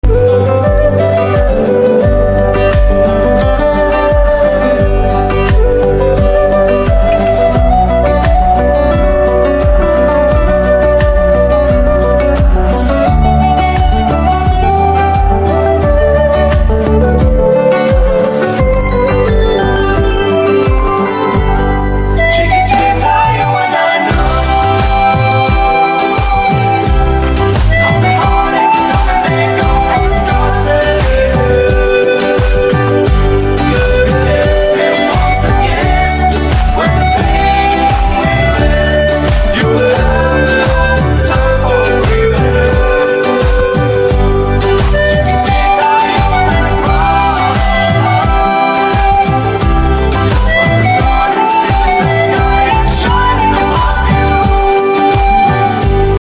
une version magnifique et entraînante